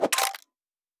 Special & Powerup (34).wav